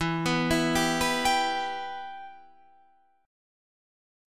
Bsus4#5/E chord